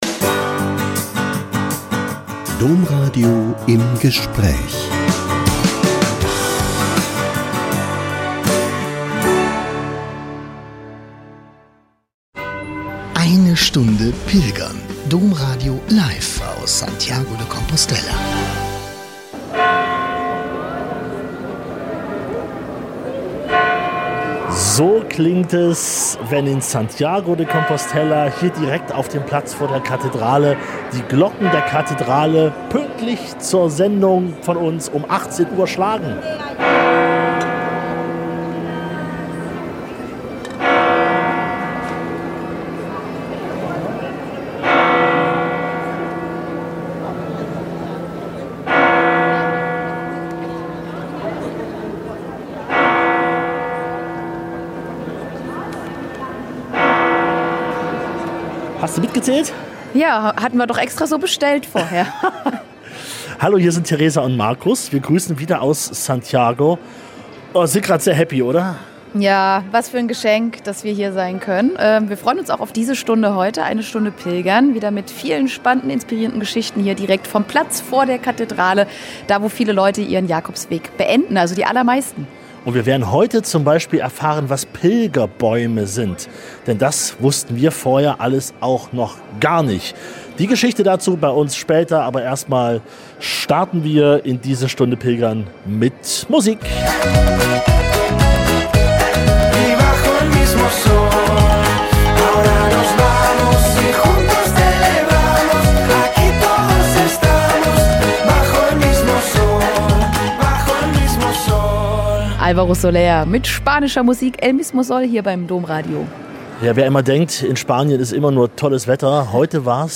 Dazu wieder viele emotionale, berührende und spannende Pilgergeschichten direkt von vor Ort.